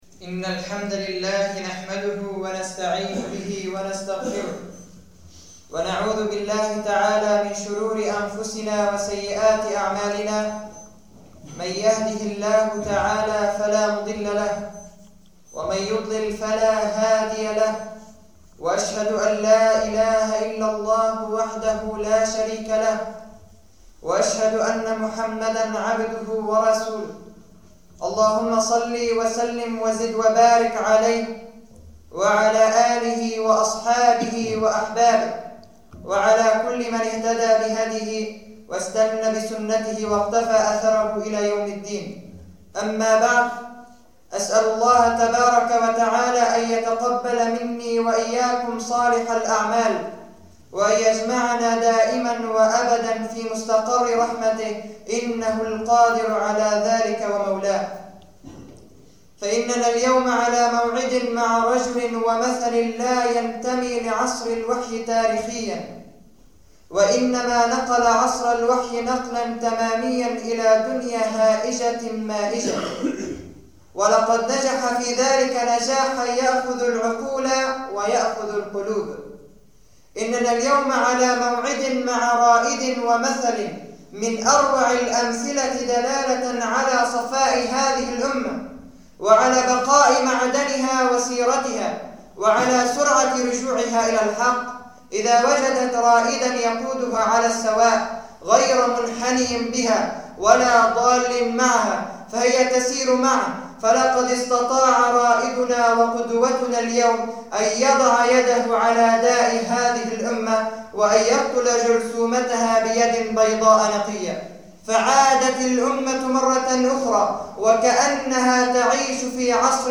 [خطبة جمعة] عمر بن عبدالعزيز رضي الله عنه